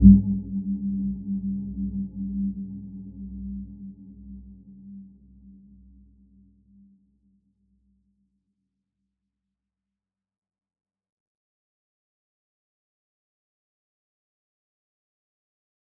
Dark-Soft-Impact-G3-f.wav